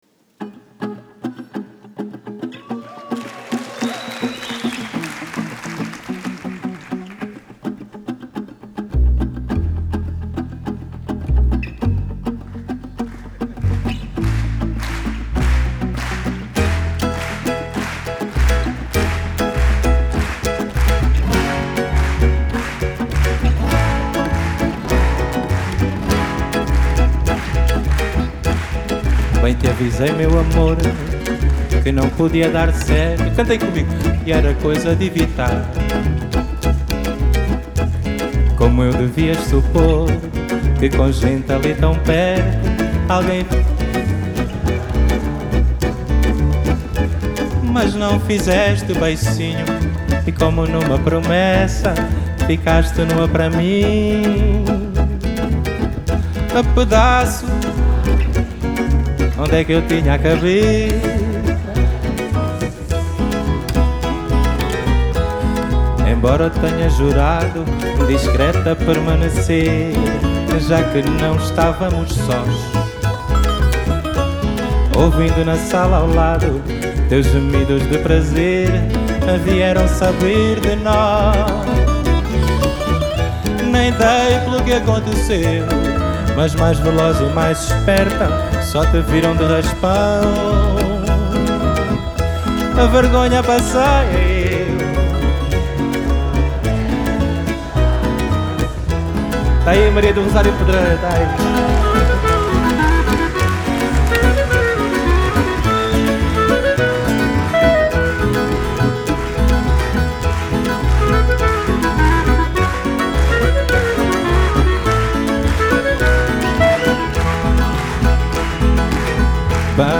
Genre: Fado, Fado Bossa Nova, Folk, World